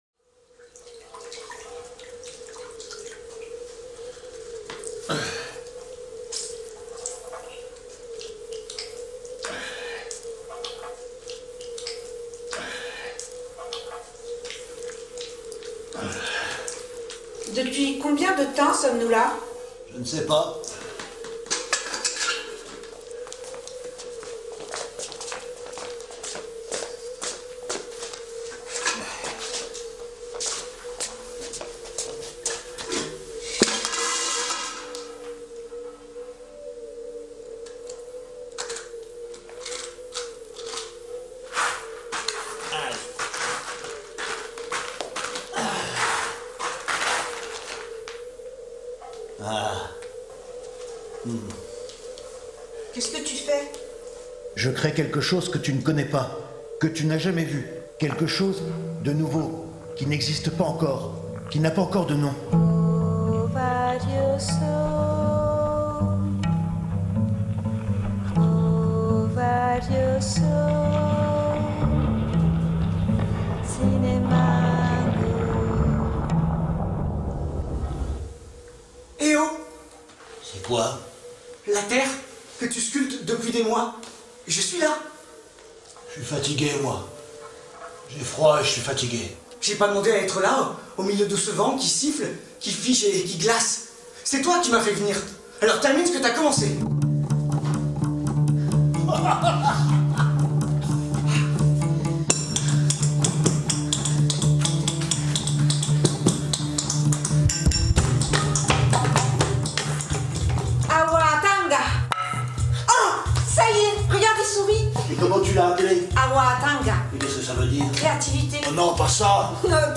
Teaser spectacle